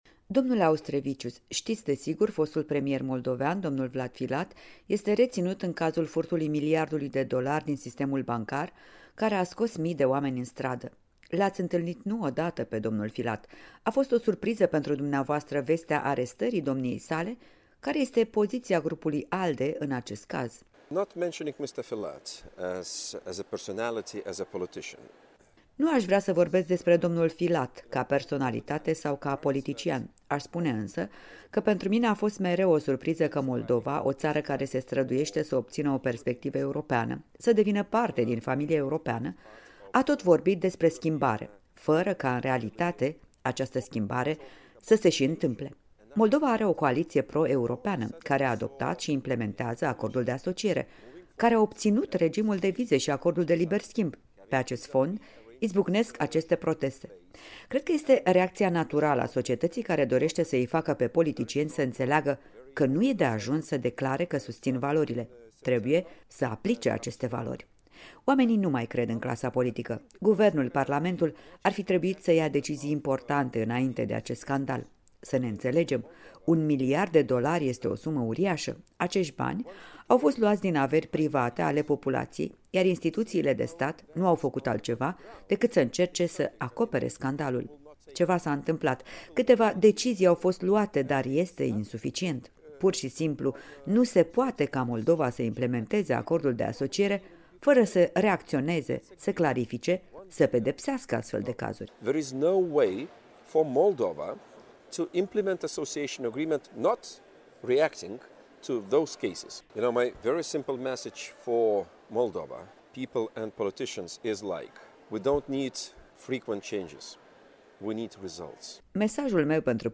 Interviu în exclusivitate cu raportorul Parlamentului European pentru Moldova.